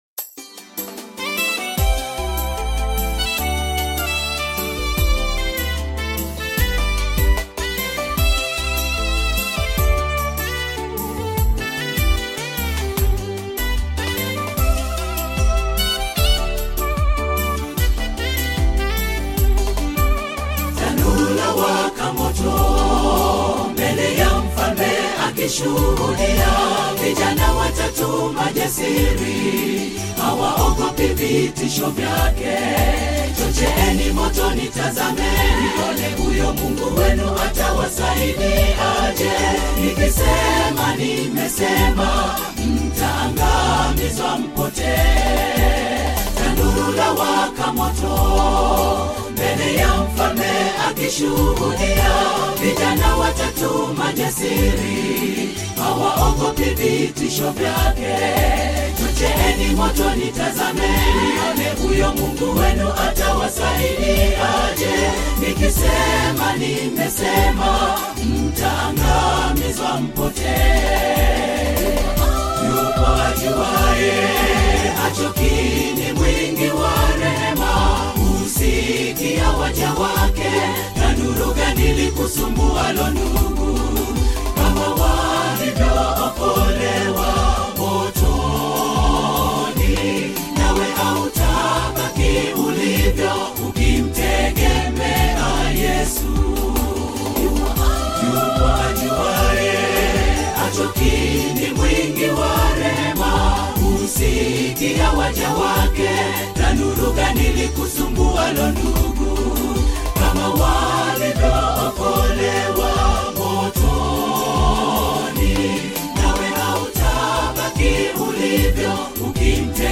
SDA gospel choir
gospel song